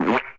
CARTOON - SPLAT 02
Category: Sound FX   Right: Both Personal and Commercial